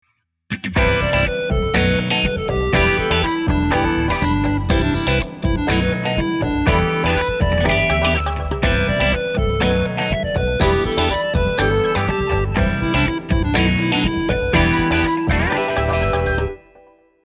今回は、生楽器の音を多く使用しているため、メロディーの音色は電子的なシンセサイザー音「ES1」に変更します。
2小節に1回の間隔で配置したものを聴いてみましょう。
▶フルートのループ追加後
Add-Flute.mp3